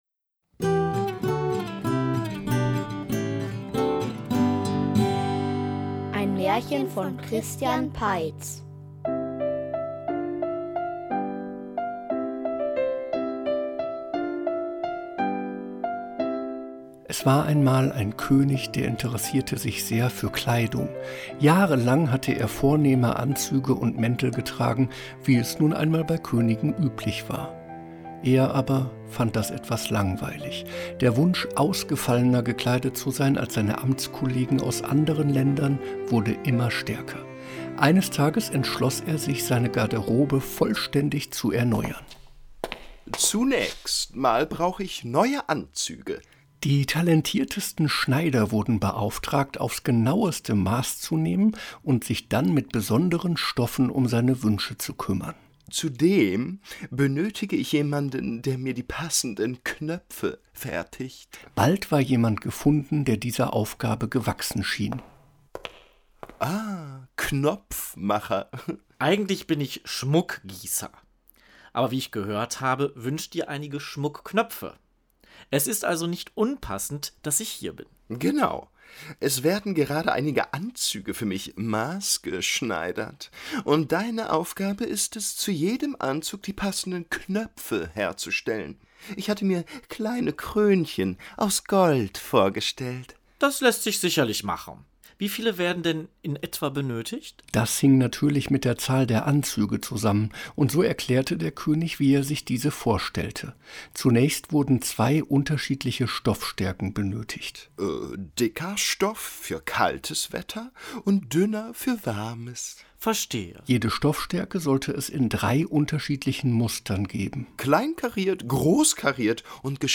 Der Text des Erzählers wurde etwas verändert, Geräusche und Musik angepasst.